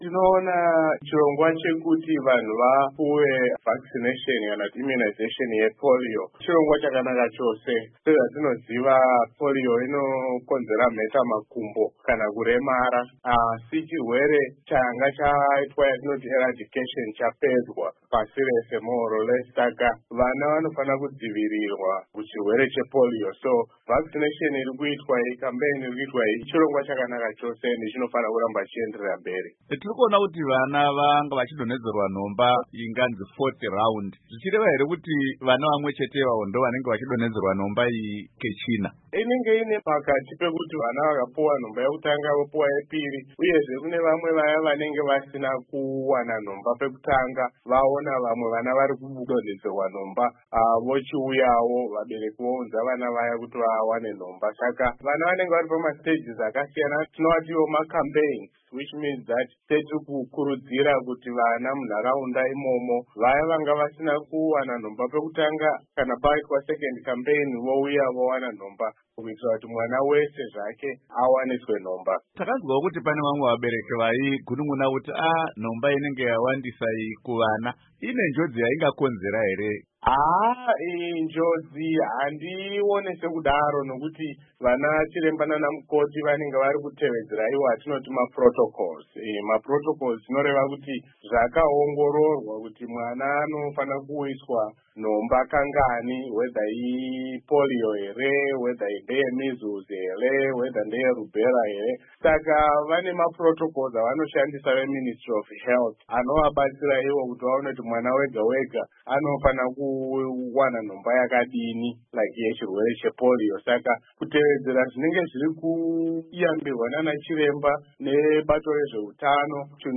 Hurukuro yezvehutano